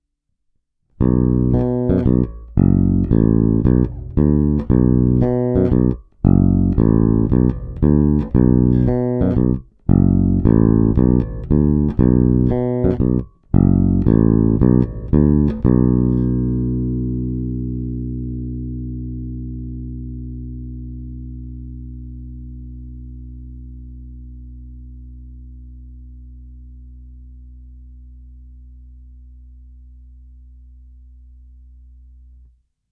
Ukázky jsou nahrány rovnou do zvukové karty a jen normalizovány. Hráno vždy nad aktivním snímačem, v případě obou pak mezi nimi.
Snímač u kobylky